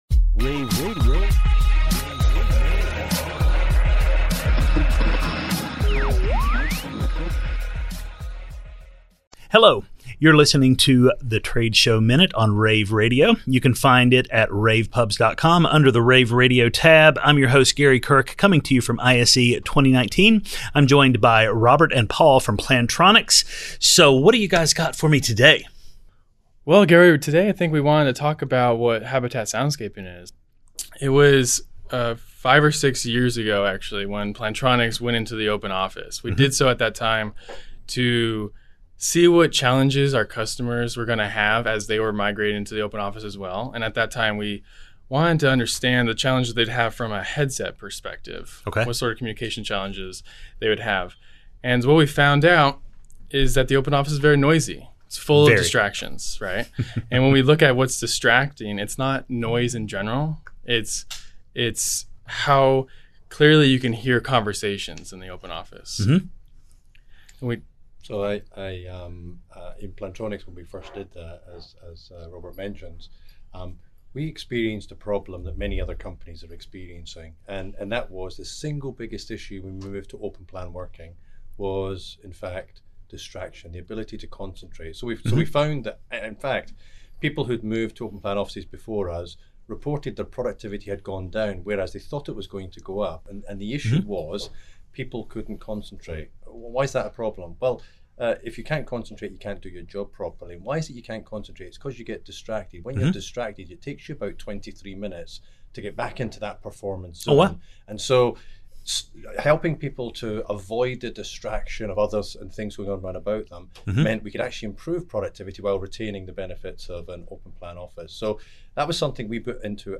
February 5, 2019 - ISE, ISE Radio, Radio, rAVe [PUBS], The Trade Show Minute,